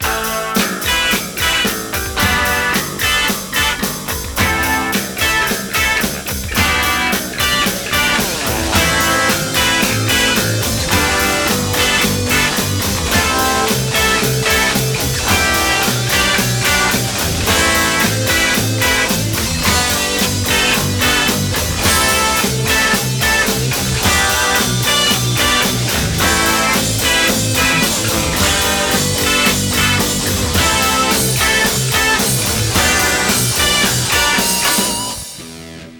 Well, this was a cool little progression written on the nylon string and brought downstairs. I hate playing on the Ovation, so I record acoustic stuff electric nowadays. A few guitars over the top, a bass line, drum beat and I've crafted another 36-second mini-masterpiece.